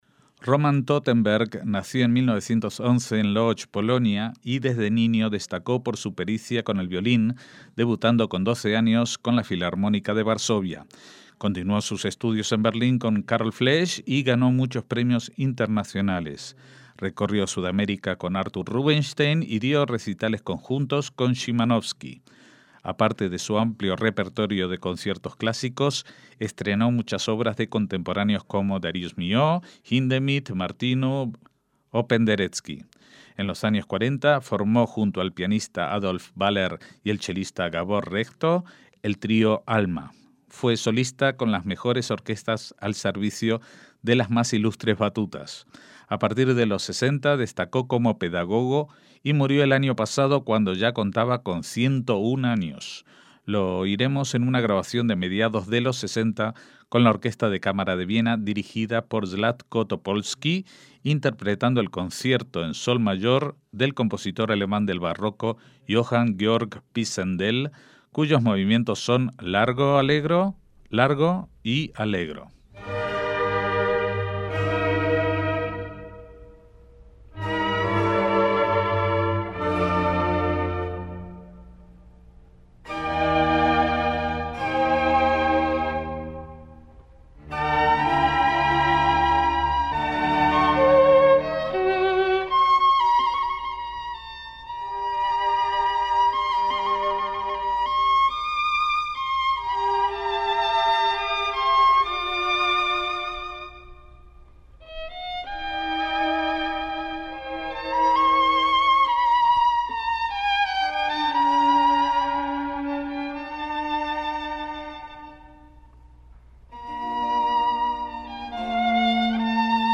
MÚSICA CLÁSICA - Roman Totenberg fue un violinista nacido en 1911 en Polonia y nacionalizado estadounidense, país donde falleció en 2012.